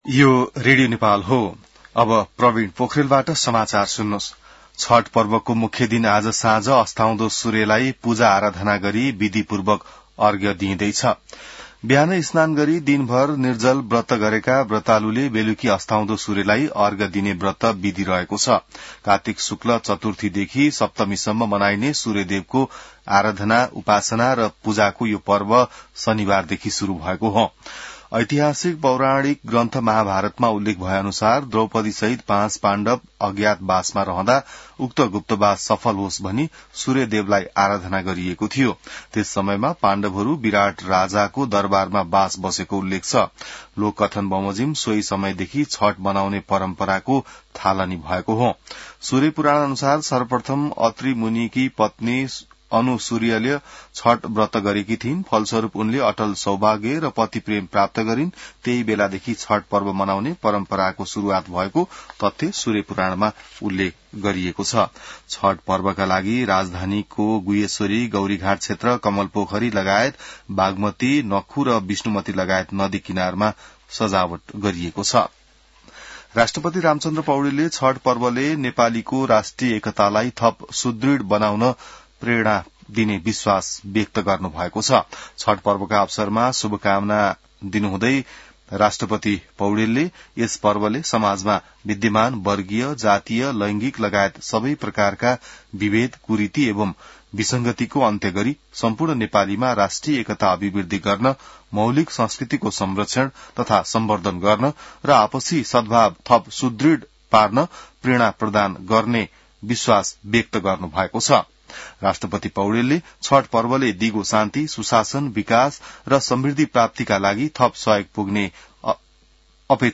बिहान ६ बजेको नेपाली समाचार : १० कार्तिक , २०८२